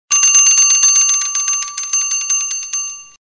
8. Древний будильник